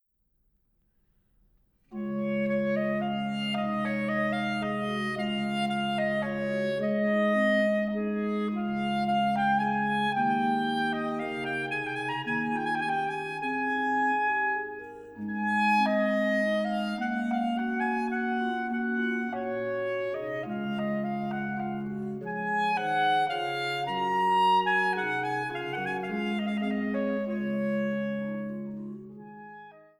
für Sopran, Clarino und B. c.